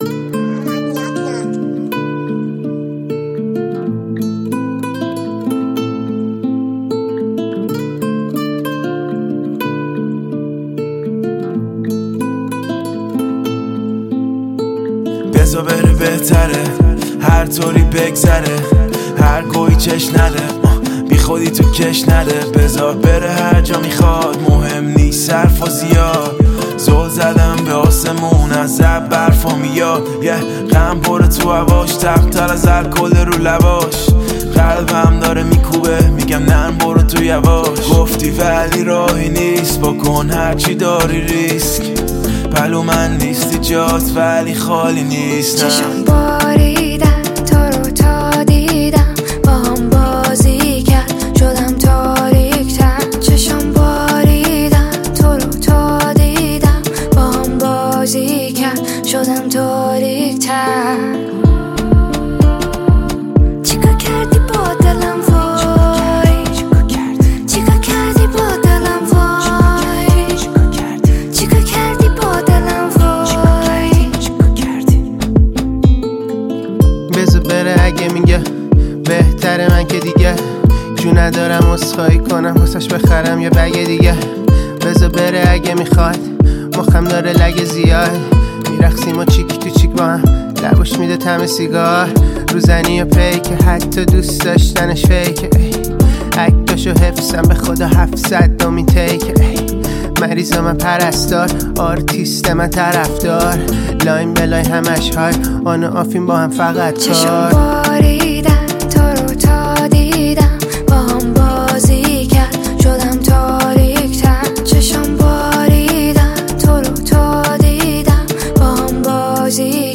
صدای خواننده زن